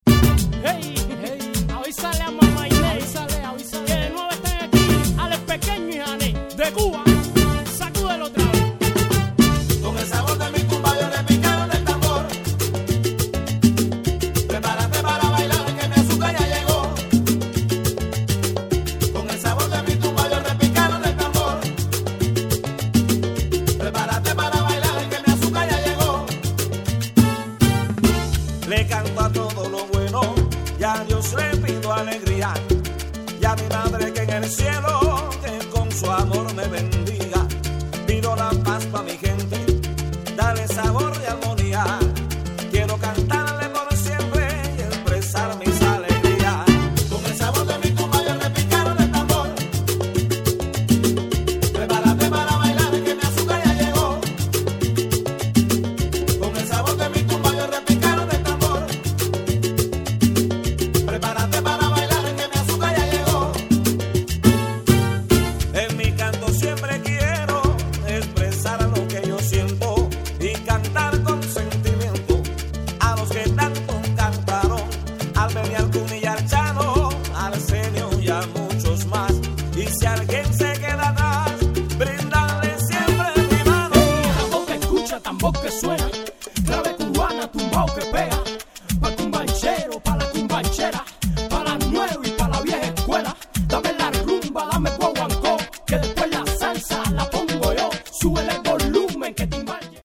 • Latin/Salsa/Reggae